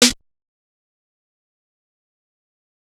Sn (PlasticBag).wav